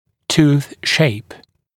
[tuːθ ʃeɪp][ту:с шэйп]форма зуба